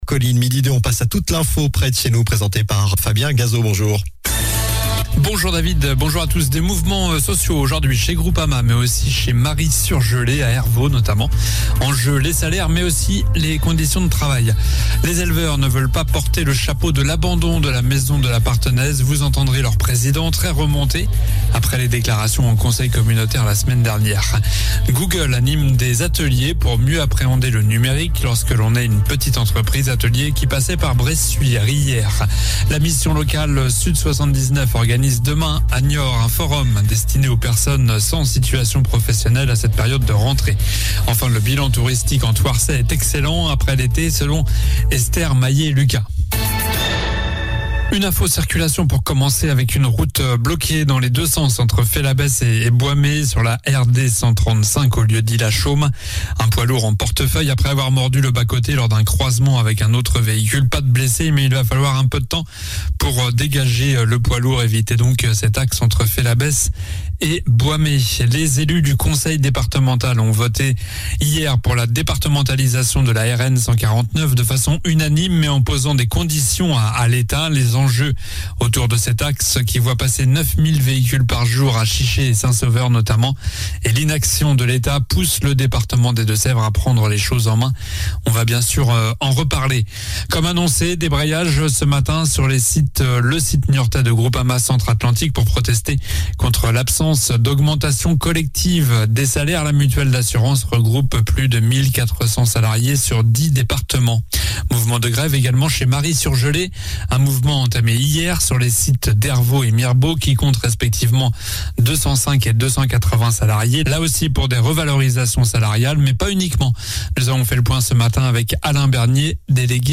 Journal du mardi 27 septembre (midi)